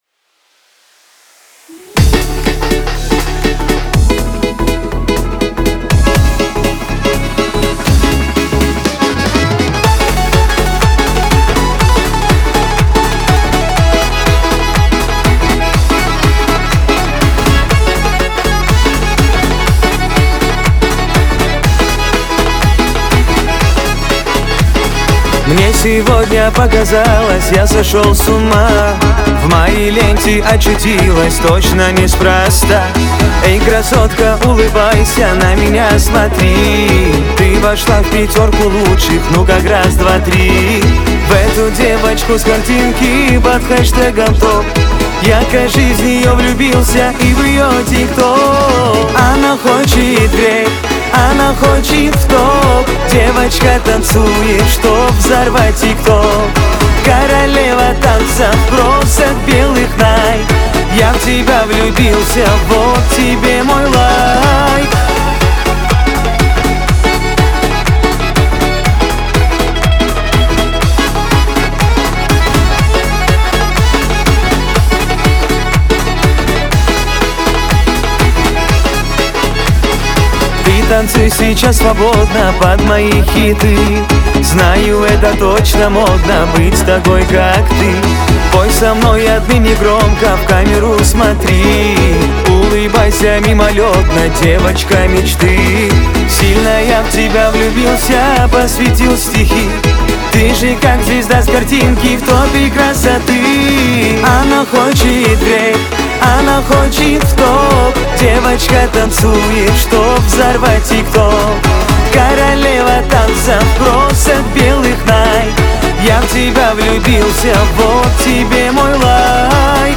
это яркая и энергичная песня в жанре поп